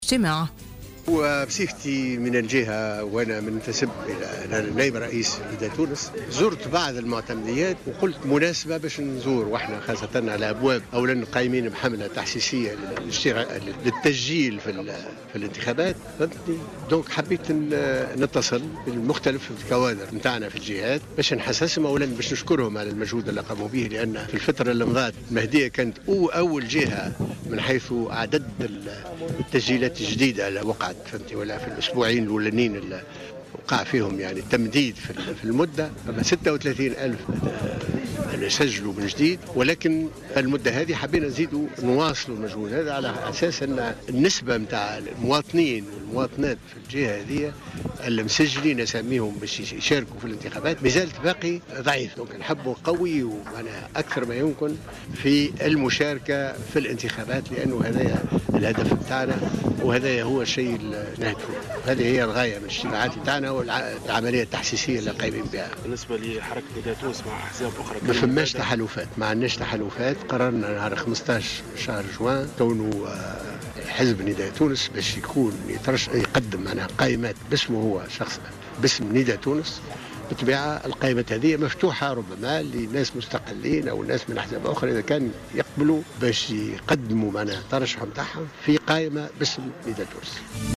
قال نائب رئيس حركة نداء تونس محمد الناصر خلال اجتماع شعبي للحركة بمدينة قصور الساف من ولاية المهدية اليوم الأحد إن الغاية من الاجتماعات المكثفة التي تقوم بها حركة نداء تونس في عدد من المدن التونسية هي التشجيع تكثيف نسبة التسجيل في الانتخابات مؤكدا إن نسبة المسجلين بقصور الساف مازالت ضئيلة جدا ويجب العمل على تقويتها.